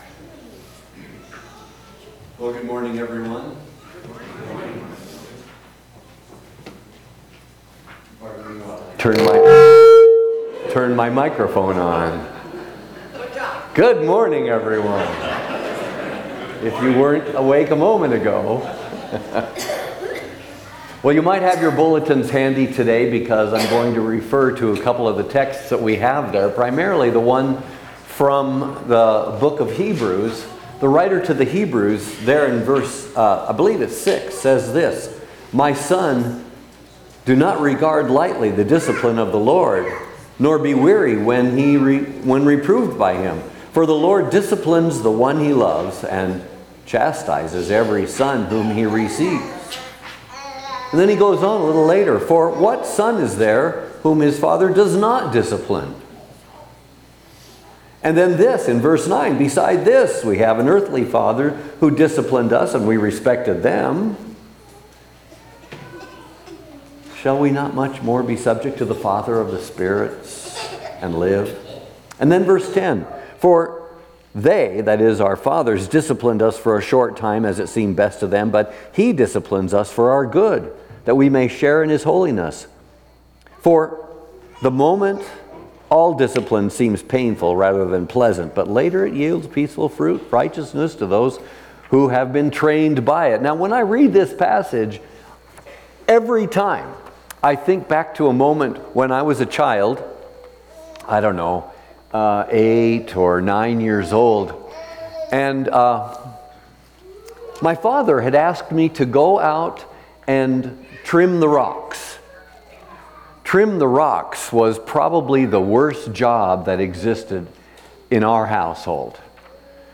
Eleventh Sunday after Pentecost&nbsp